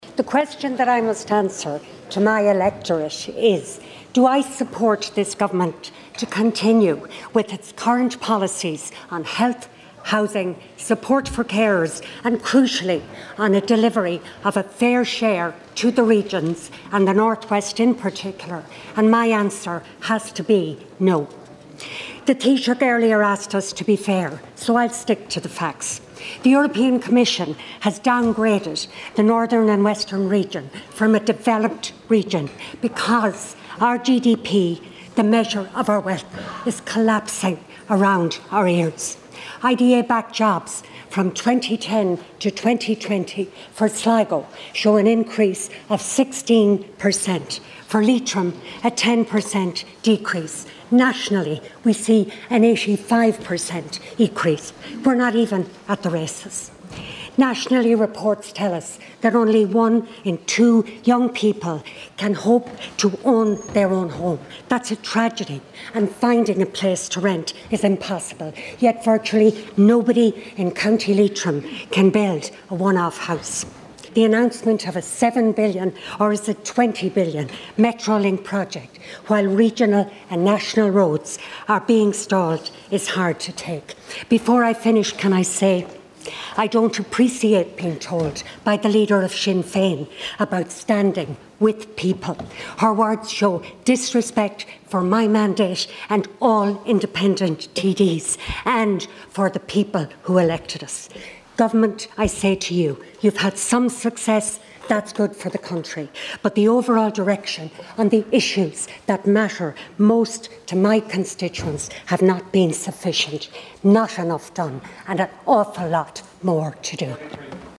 Speaking in the Dail during the motion on confidence last night, Independent TD Marian Harkin also claimed that virtually nobody in Co. Leitrim is being permitted to build one off housing.